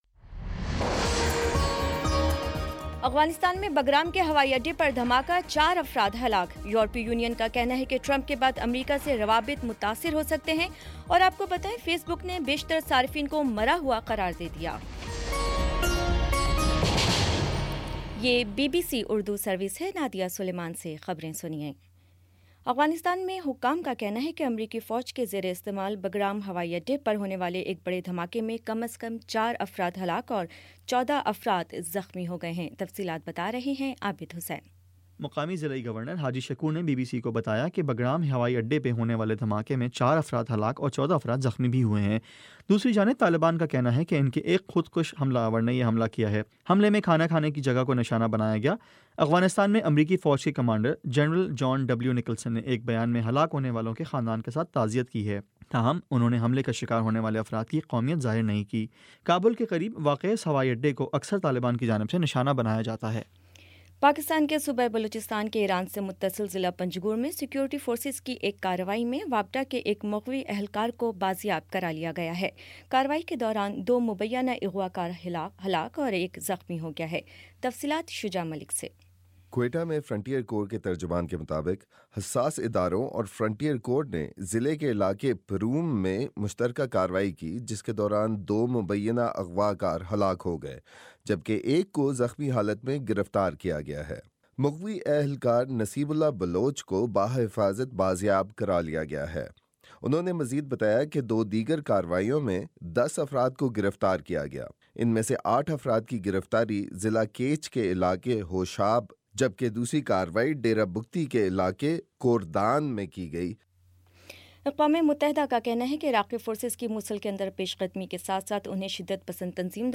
نومبر 12 : شام پانچ بجے کا نیوز بُلیٹن